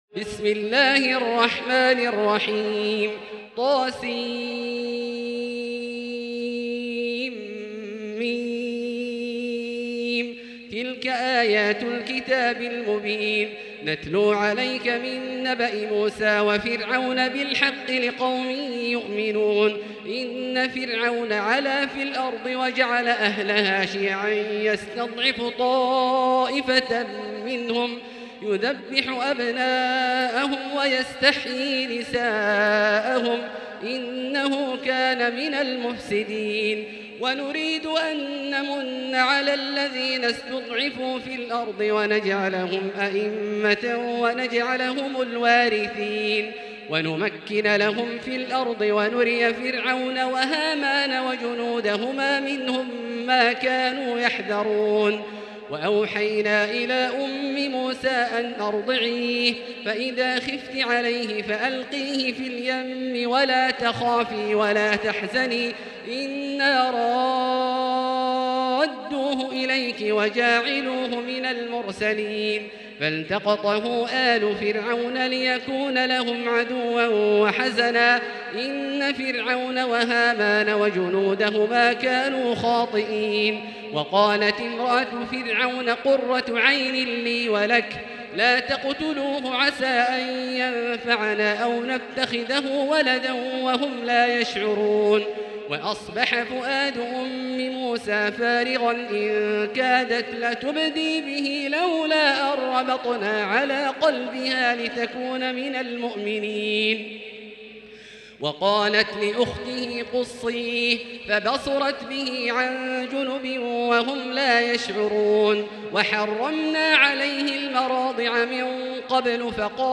المكان: المسجد الحرام الشيخ: فضيلة الشيخ عبدالله الجهني فضيلة الشيخ عبدالله الجهني القصص